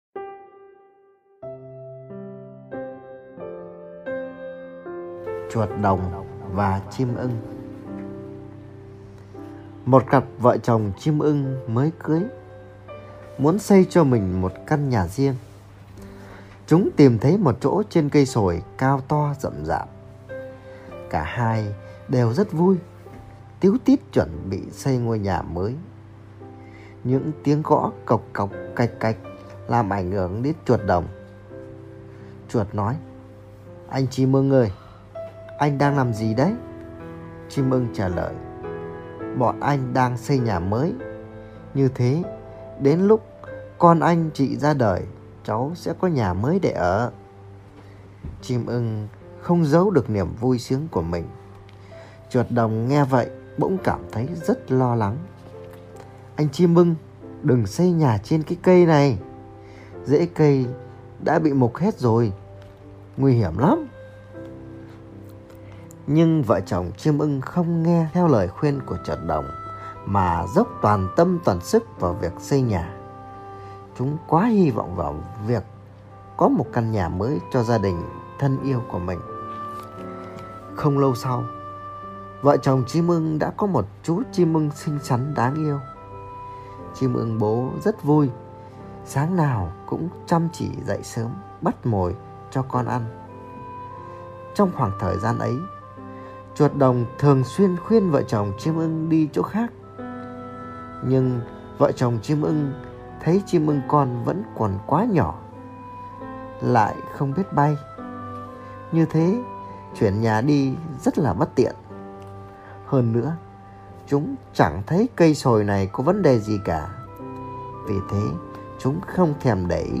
Sách nói | Chim ưng và chuột đồng